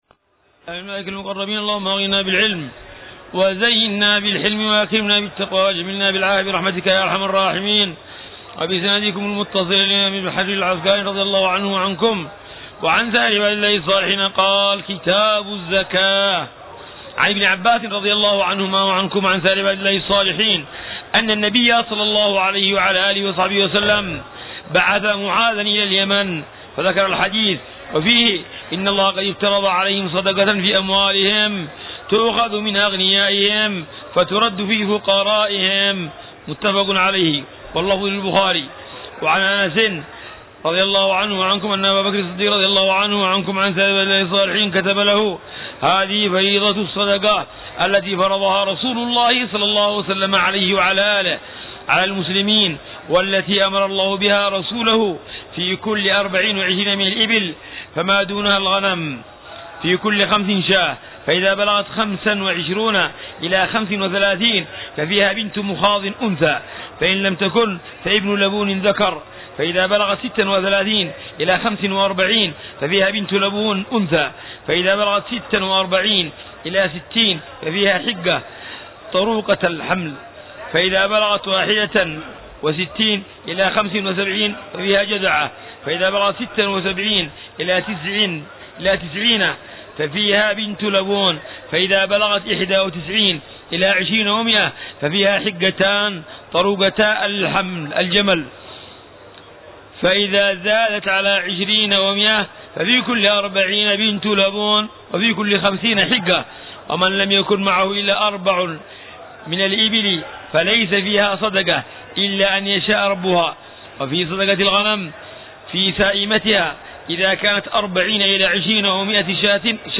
درس بلوغ المرام - 141 - باب الزكاة
شرح الحبيب عمر بن حفيظ على كتاب بلوغ المرام من أدلة الأحكام للإمام الحافظ أحمد بن علي بن حجر العسقلاني، مختصر